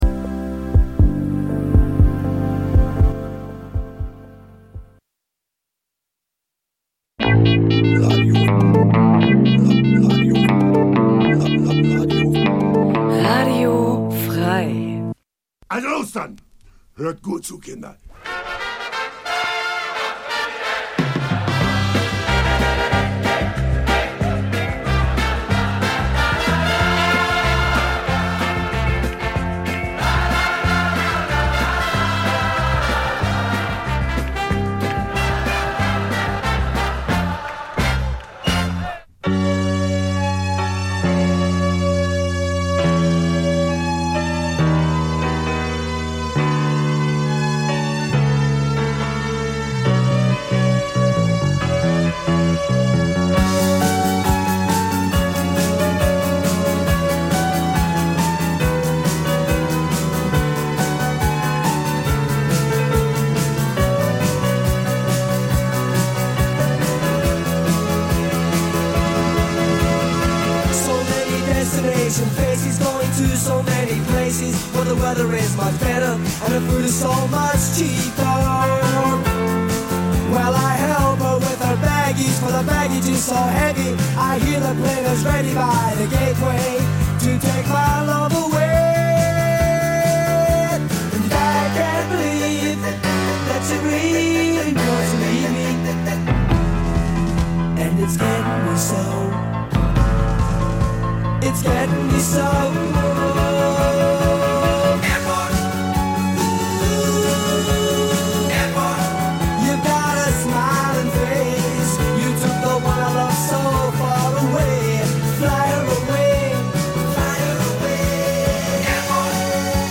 Easy Listening Dein Browser kann kein HTML5-Audio.